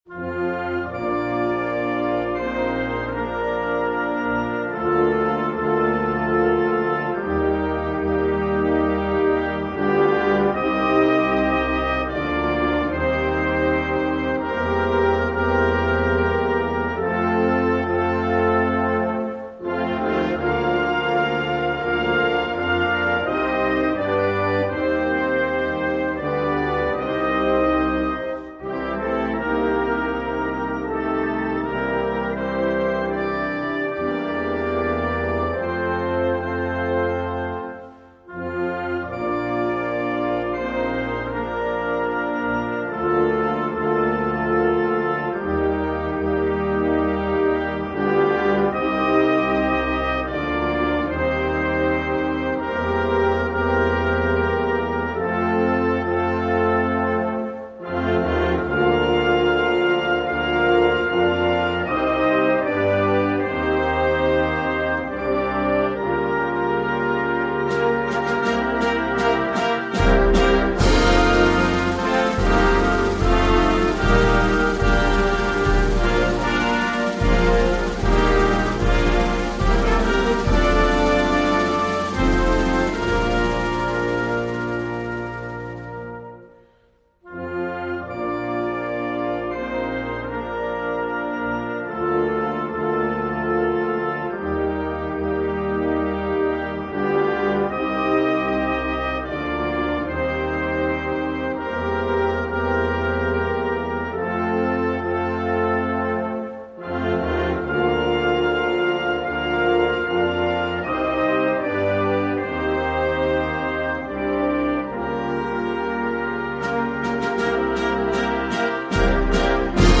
National_Anthem_of_Marshall_Islands_by_US_Navy_Band.mp3